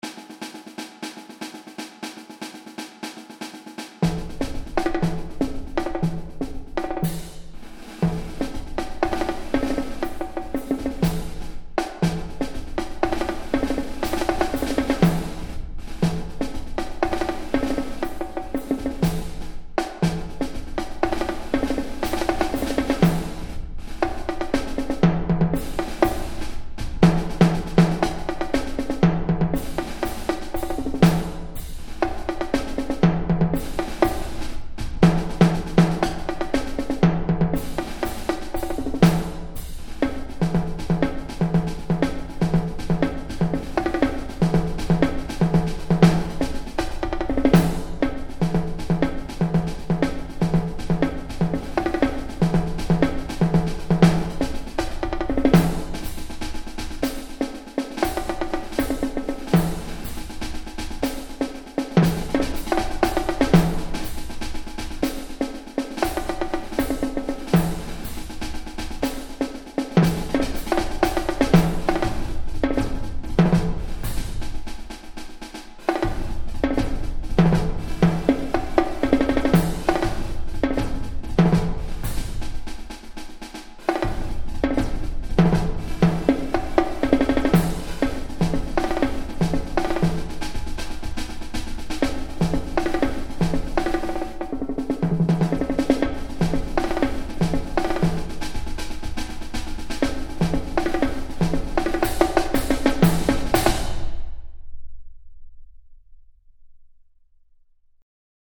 Ongestemd Marcherend Slagwerk
Snare drum Quad Toms Cymbals Bass drum